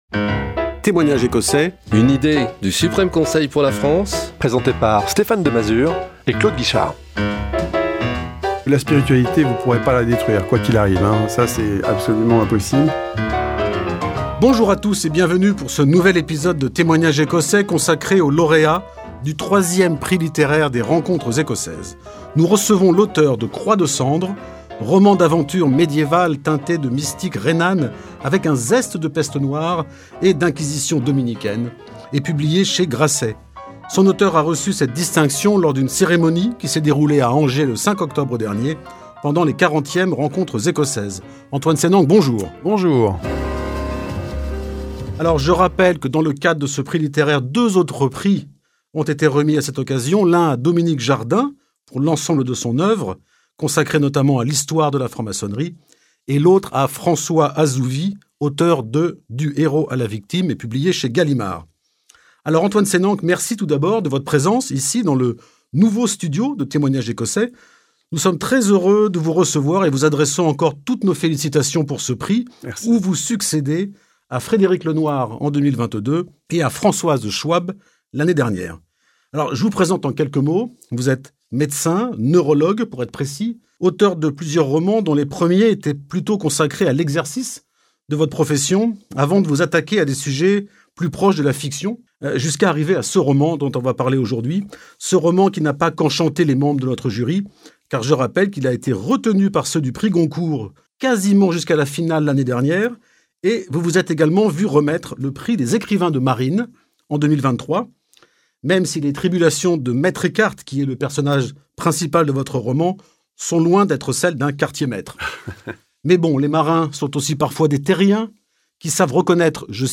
Témoignages Écossais propose des entretiens audio que vous pouvez écouter où et quand vous le souhaitez. Nous recevons des personnalités qui viennent témoigner de leur expérience et échanger sur des thèmes majeurs liés à la spiritualité, l'ésotérisme, la littérature...
Ce sont des entretiens « vrais », sans langue de bois, dynamiques et originaux.